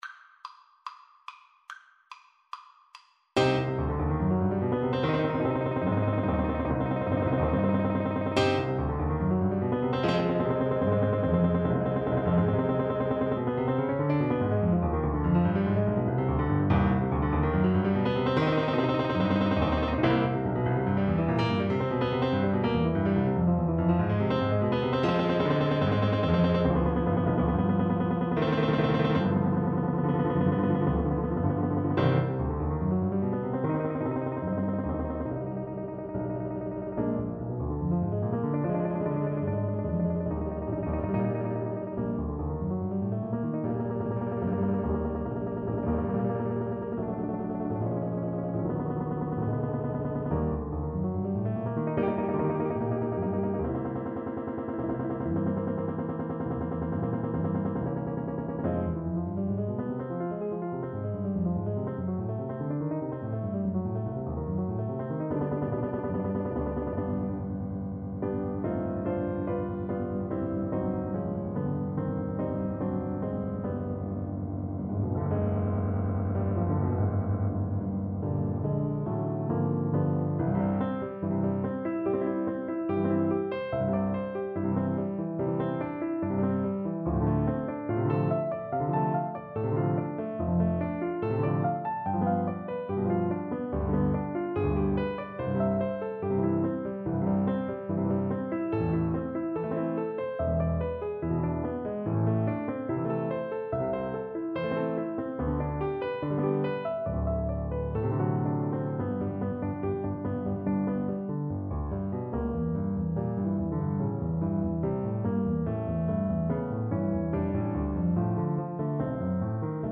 Allegro moderato (=144) (View more music marked Allegro)
4/4 (View more 4/4 Music)
Classical (View more Classical French Horn Music)